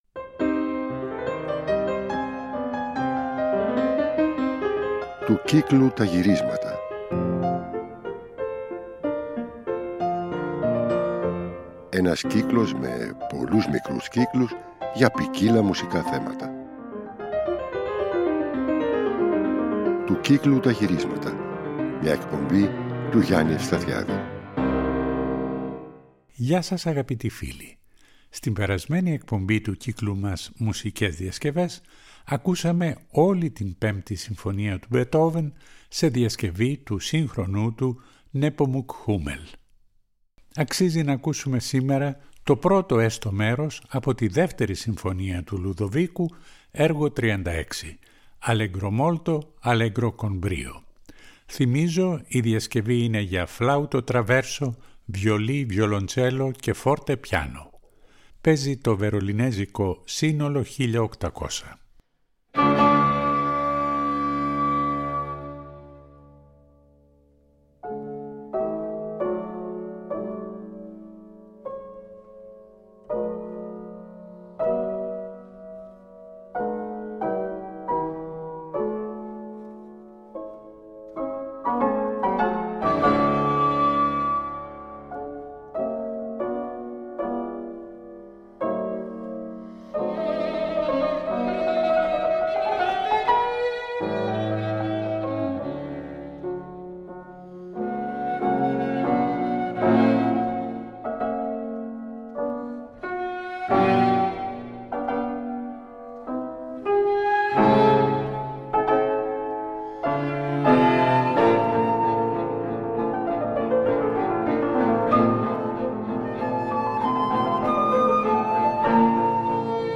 για φλάουτο τραβέρσο, βιολί, βιολοντσέλο και forte piano.
σε μία πρωτότυπη διασκευή για πιάνο, έγχορδα και ακορντεόν
ηχογραφημένα ζωντανά στην πλατεία Αγίου Μάρκου της Βενετίας
Θα ακολουθήσουν κλασικά έργα σε τσιγγάνικες διασκευές
ορχηστρικές διασκευές